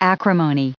136_acrimony.ogg